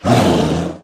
Minecraft Version Minecraft Version 1.21.5 Latest Release | Latest Snapshot 1.21.5 / assets / minecraft / sounds / mob / polarbear / warning1.ogg Compare With Compare With Latest Release | Latest Snapshot
warning1.ogg